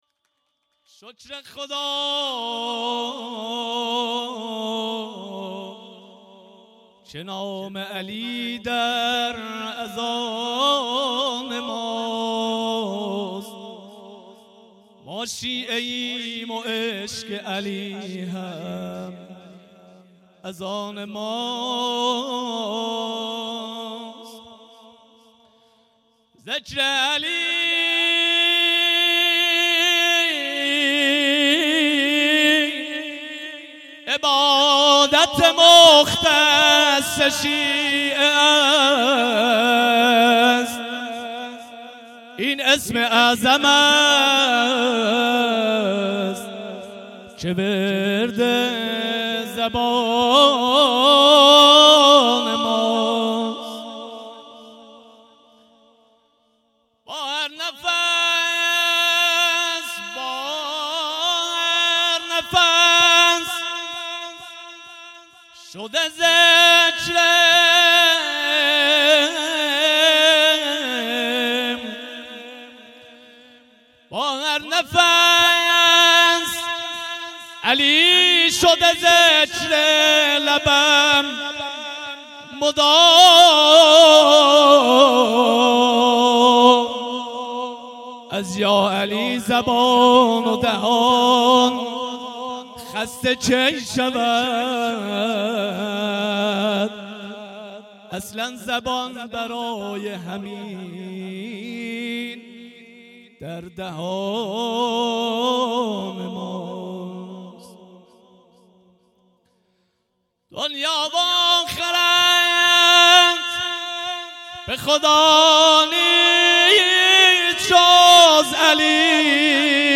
مدح خوانی | شکر خدا نام علی